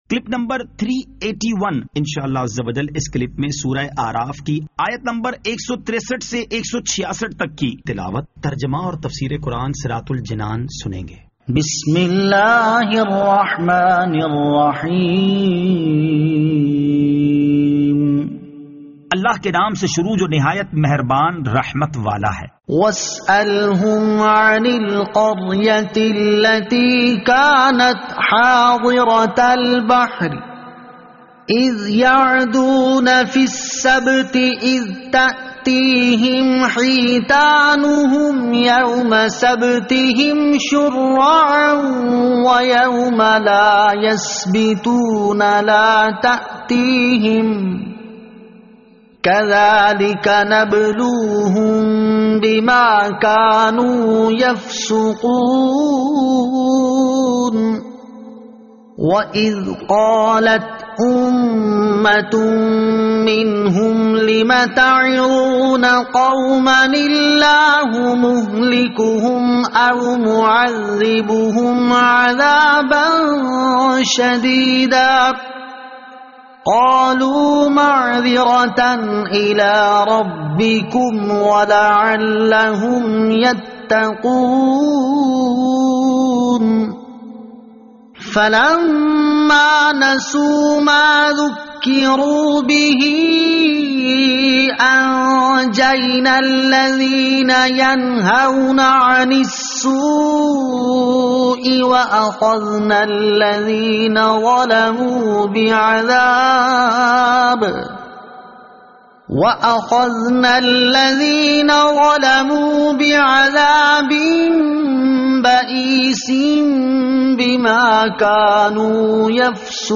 Surah Al-A'raf Ayat 163 To 166 Tilawat , Tarjama , Tafseer